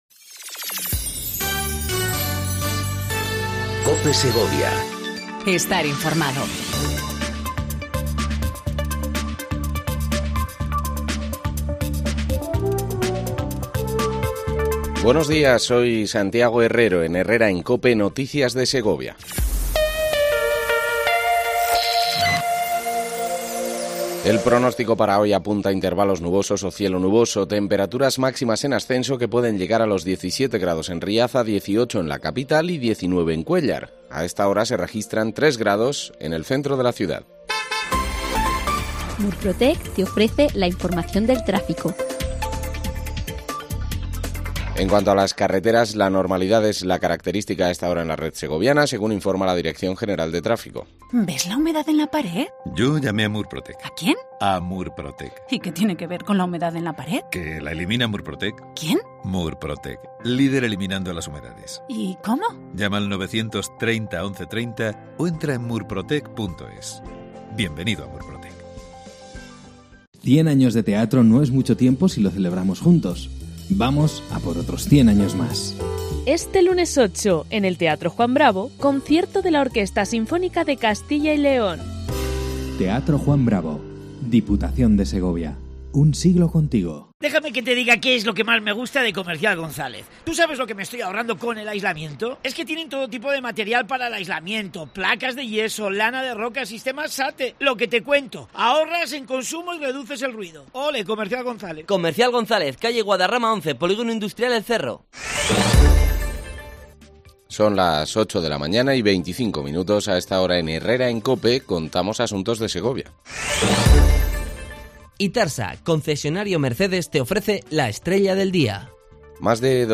AUDIO: Segundo informativo local cope segovia